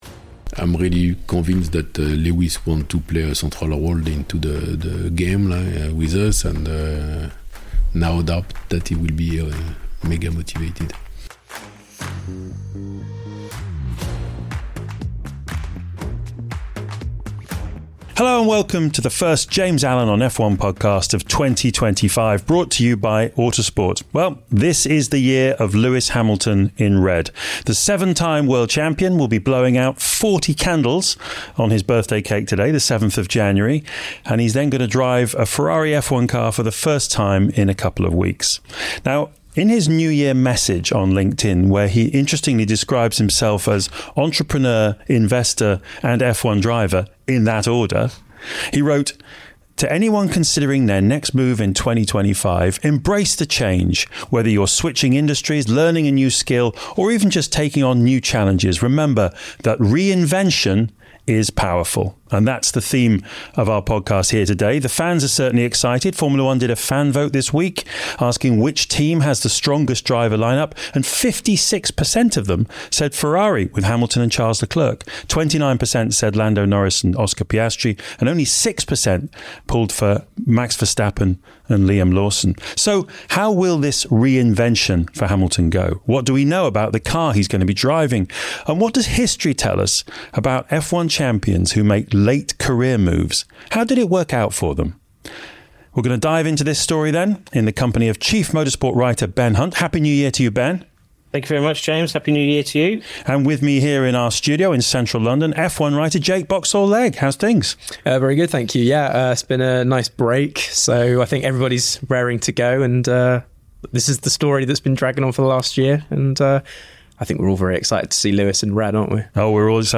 With James in the studio